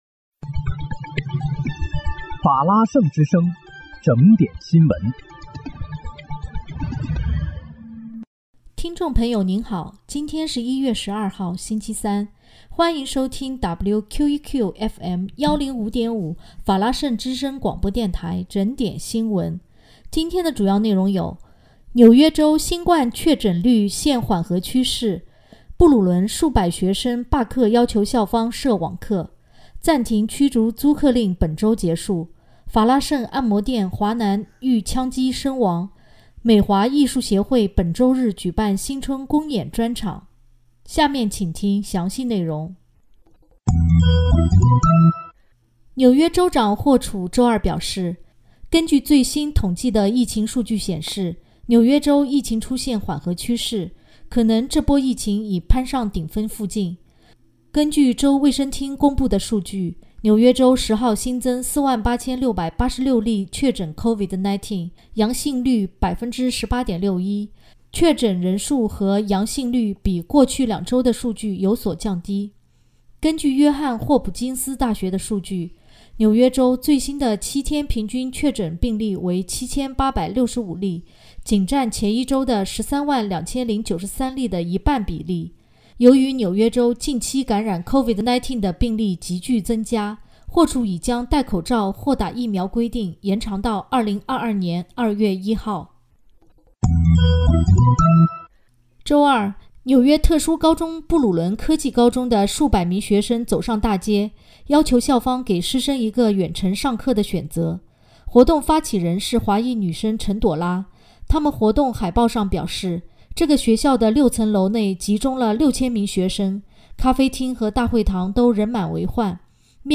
1月12日（星期三）纽约整点新闻